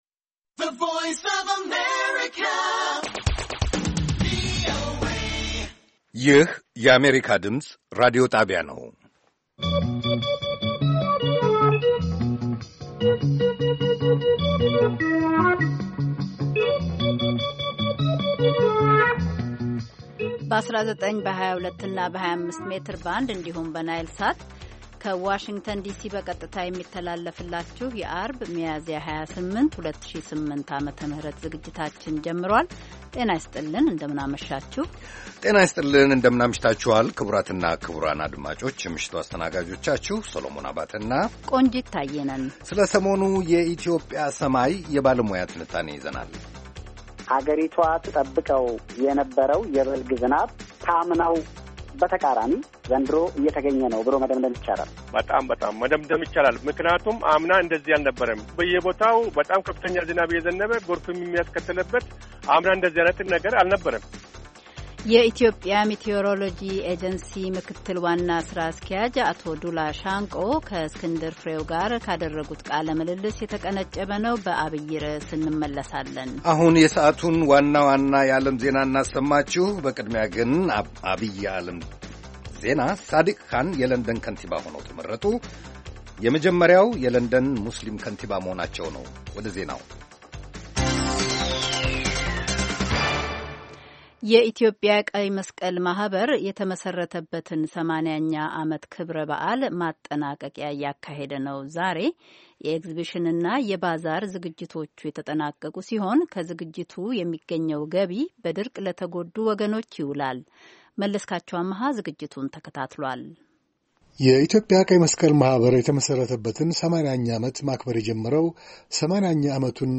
ቪኦኤ በየዕለቱ ከምሽቱ 3 ሰዓት በኢትዮጵያ ኣቆጣጠር ጀምሮ በአማርኛ፣ በአጭር ሞገድ 22፣ 25 እና 31 ሜትር ባንድ የ60 ደቂቃ ሥርጭቱ ዜና፣ አበይት ዜናዎች ትንታኔና ሌሎችም ወቅታዊ መረጃዎችን የያዙ ፕሮግራሞች ያስተላልፋል። ዐርብ፡- እሰጥ አገባ፣ አፍሪካ በጋዜጦች፡ አጥቢያ ኮከብ (የማኅበረሰብ ጀግኖች)